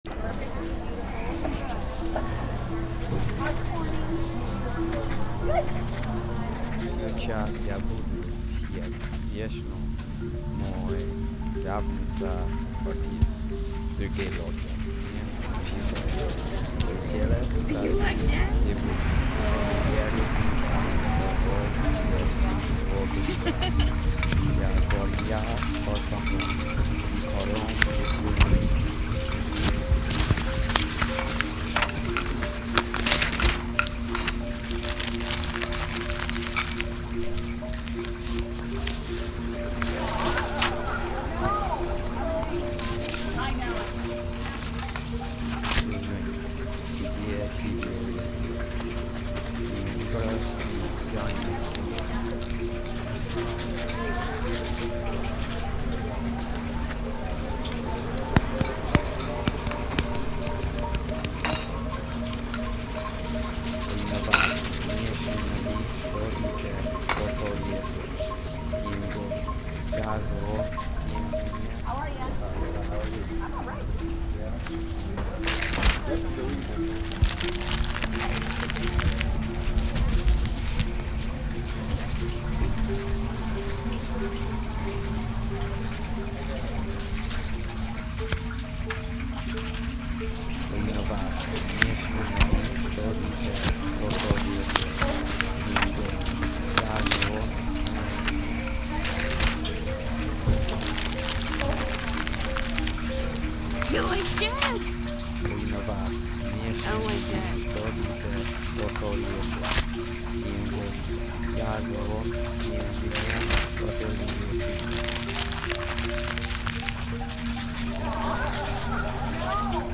Coffee Shop Soundscape
Using sounds such as an espresso machine, a marimba, rain and soft conversation, this soundscape is meant to capture the serenity in the business atmosphere of a coffee shop.
The main requirement was that we needed to go into the field and record ten distinct sounds, and merge them into a smooth and cohesive soundscape.
So, my soundscape took on the shape of a writer scribbling away, ripping the page written and crumpling it, and an awareness of the coffee machine and hot water boiling and the silverware clattering on plates.